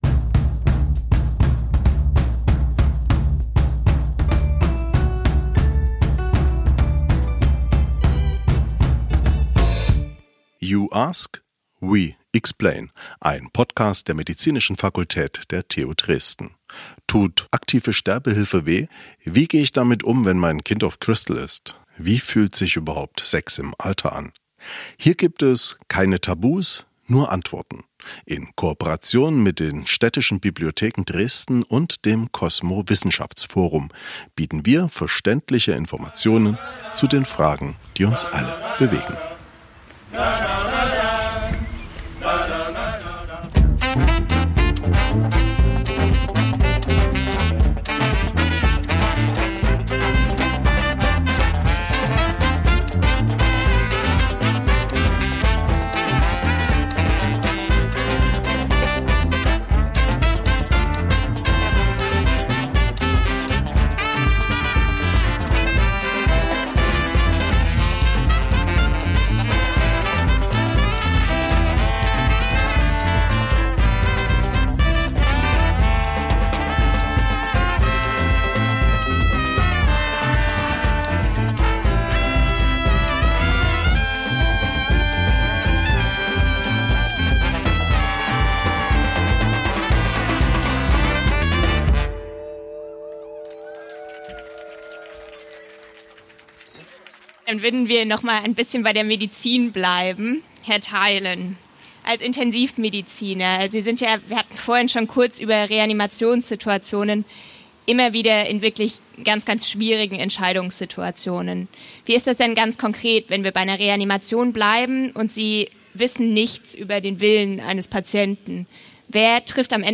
Musikalische Begleitung von der Banda Comunale Geben Sie uns ihr Feedback- einfach, schnell und anonym.